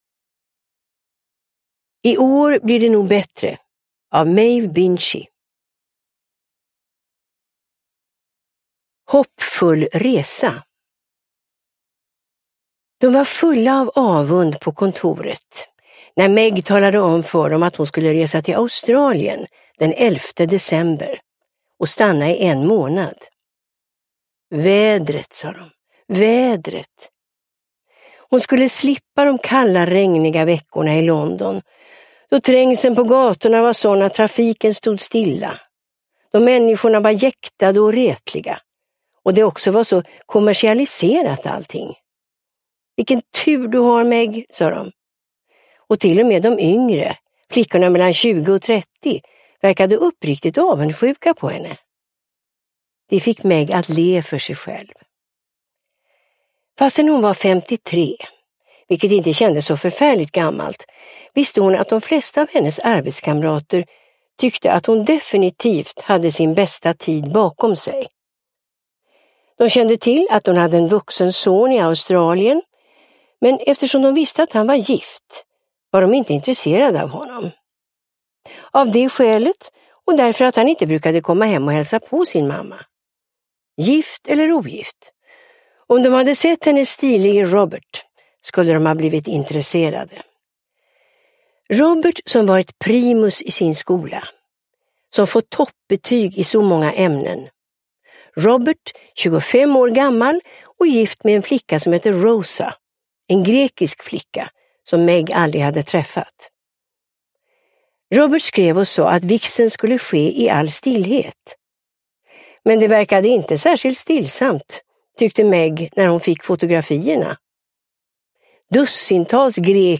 I år blir det nog bättre – Ljudbok – Laddas ner